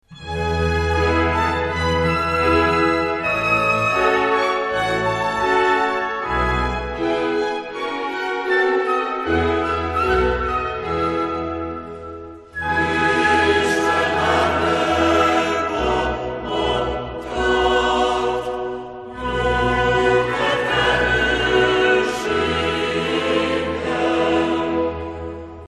Ennek az évnyitónak méltó folytatása hangzott el - a felvételünkön szereplő kórus közreműködésével - még abban a hónapban a budavári Mátyás-templomban a Magyar Kultúra Napjának estéjén.